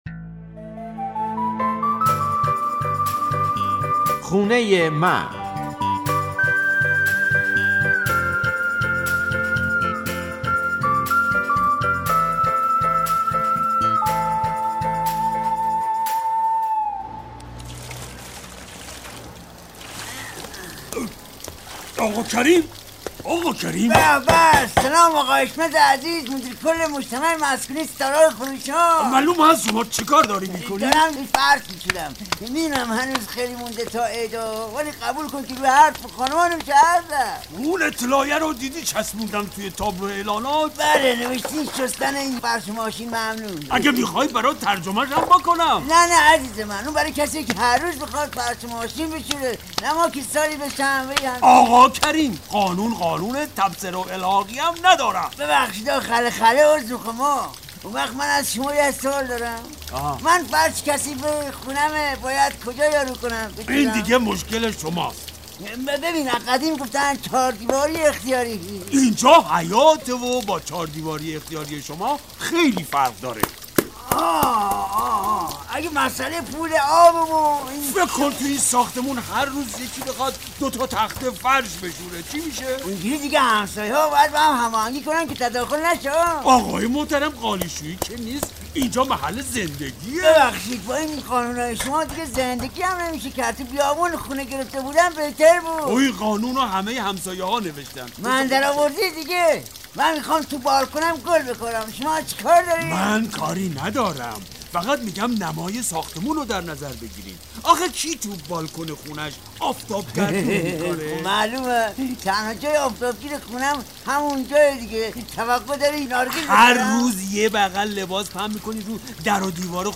نمایش رادیویی «خونه من» درباره ساکنان یک آپارتمان است که به خاطر شستن فرش در حیاط با هم بحث می کنند.